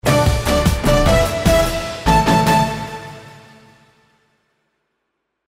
Play, download and share Survey Battles Win original sound button!!!!